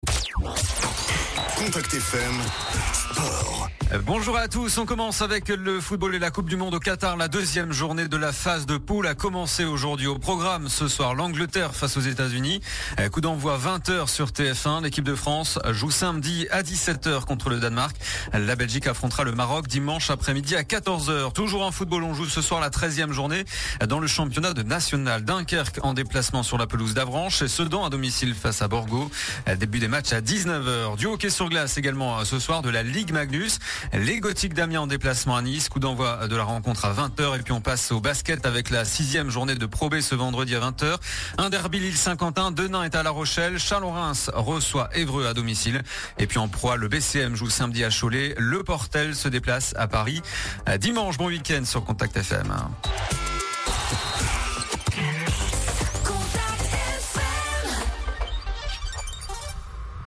Le journal des sports du vendredi 25 novembre